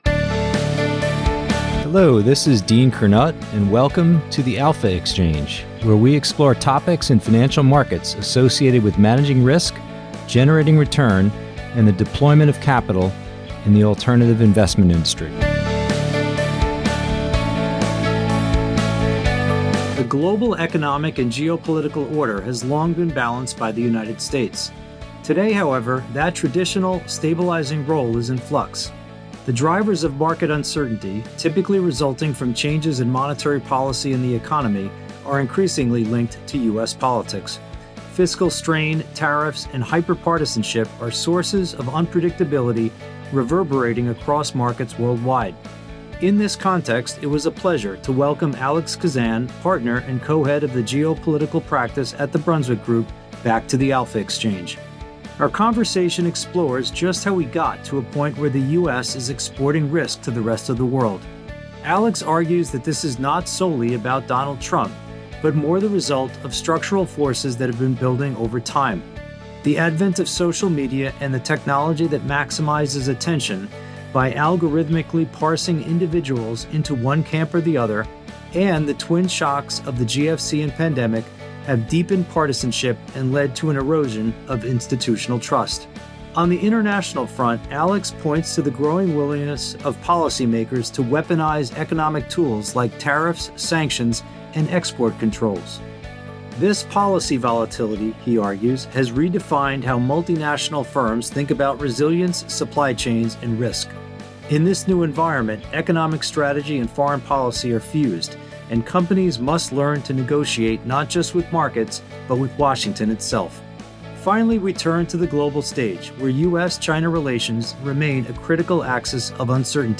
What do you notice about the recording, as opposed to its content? (Originally aired on WFMU on May 1st, 2007) New to the Best Show?